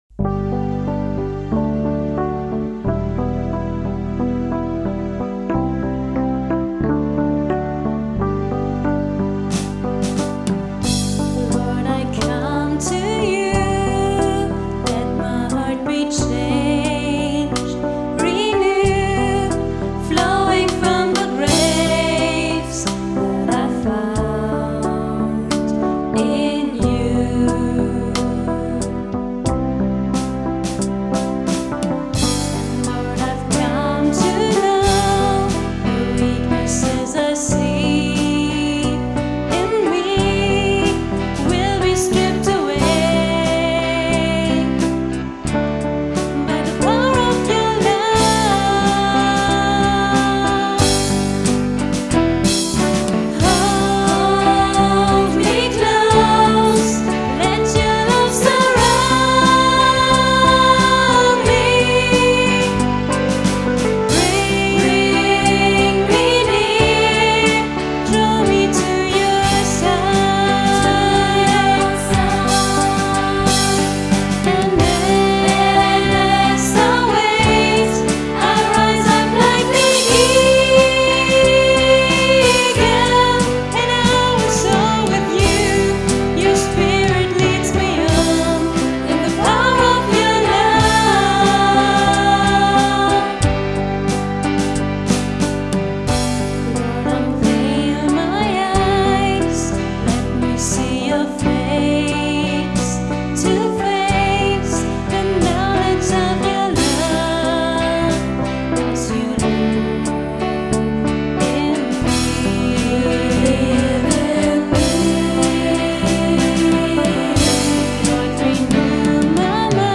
Genre: NGL.